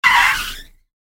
Реалистичные записи передают гул двигателей, скрип тормозов и другие детали, создавая эффект присутствия на взлетно-посадочной полосе.
Скрежет колес при касании самолета посадочной полосы